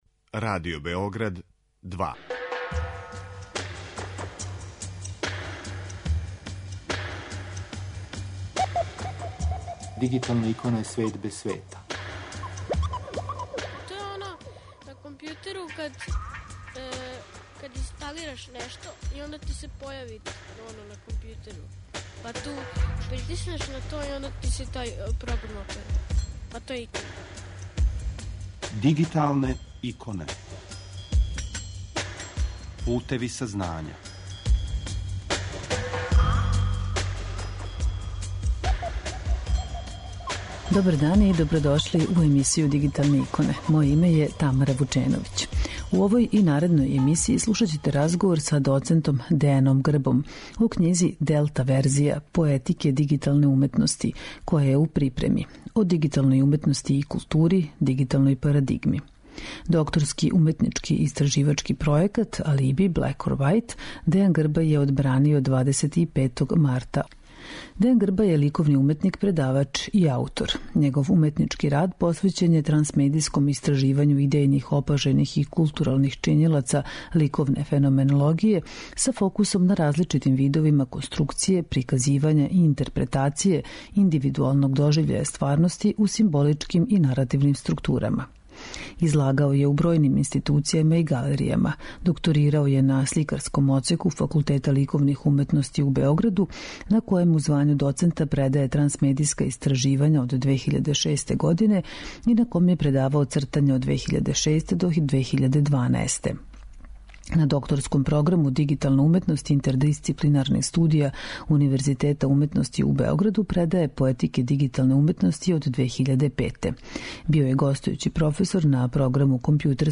Слушаћете први део разговора